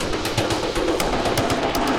Index of /musicradar/rhythmic-inspiration-samples/120bpm
RI_DelayStack_120-01.wav